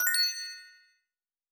Coins (22).wav